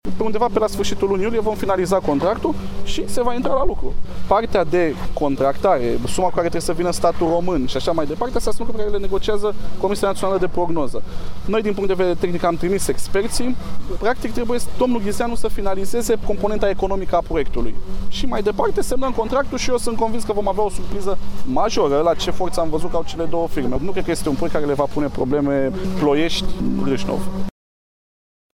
Ministrul Transporturilor, Răzvan Cuc a declarat astăzi la Brașov că pentru DN 73 se lucrează la rezilierea contractului și că se vor începe lucrări în regim propriu pentru finalizarea acestei lucrări: